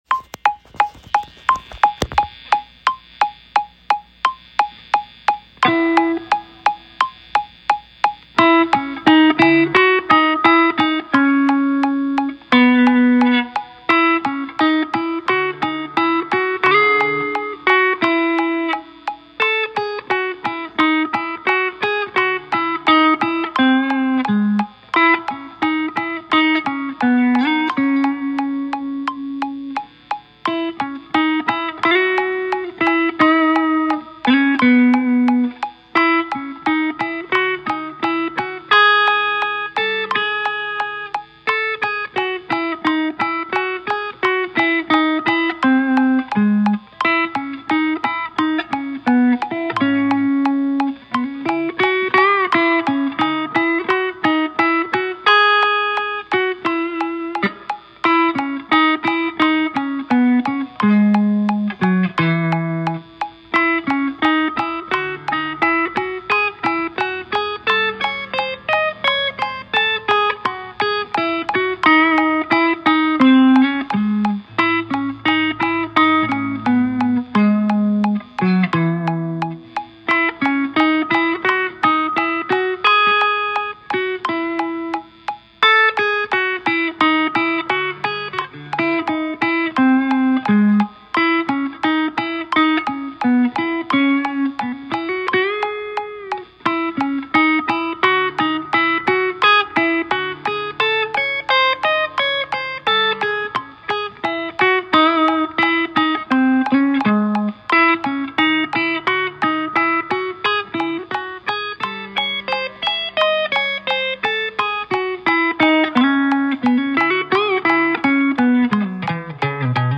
a recording of some jamming on a practice amp over a metro-gnome because I always ...